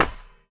stone_hit.ogg